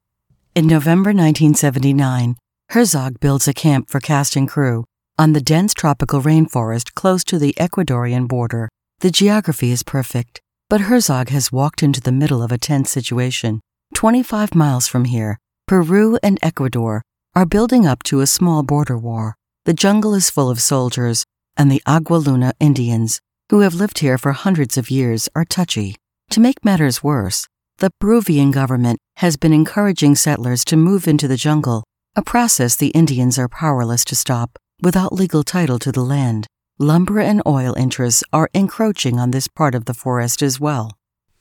Female
My voice is very versatile, warm, conversational, and real.
Documentary
Words that describe my voice are Warm, Conversational, Sophisticated.